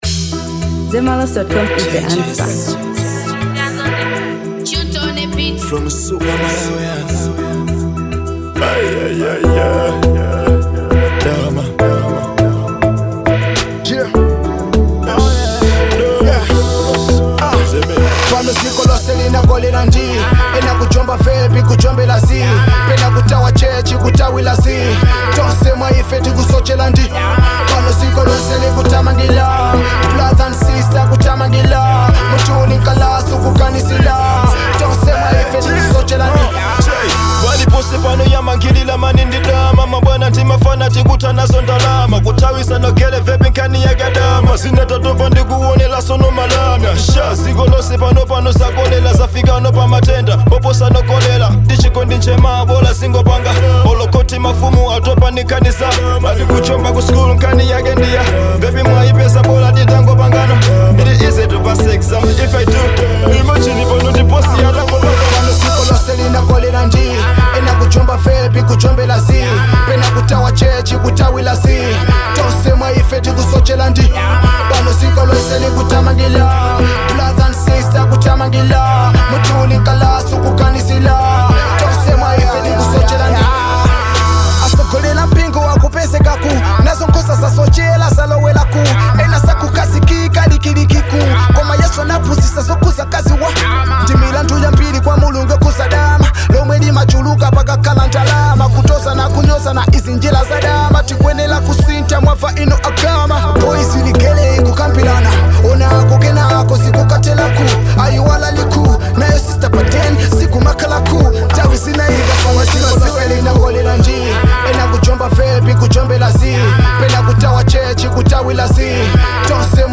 Kwaito|Afrobeats|Amapiano|Dancehall • 2025-07-12